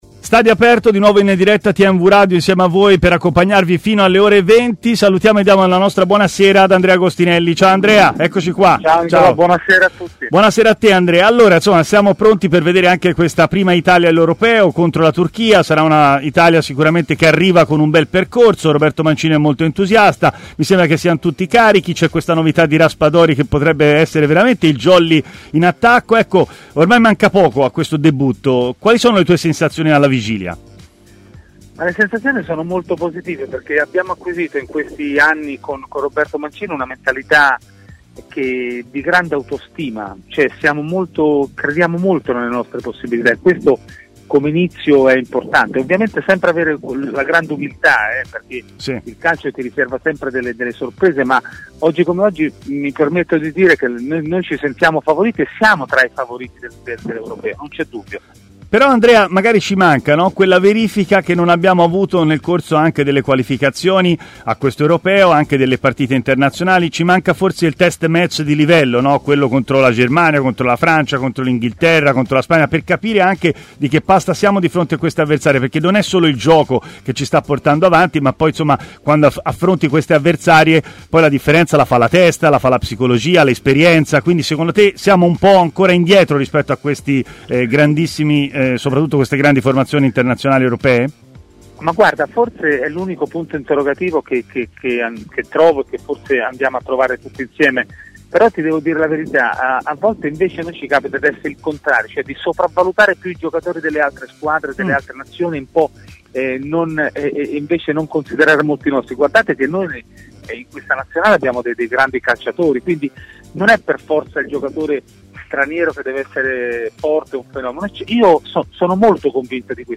è così intervenuto a Stadio Aperto, trasmissione di TMW Radio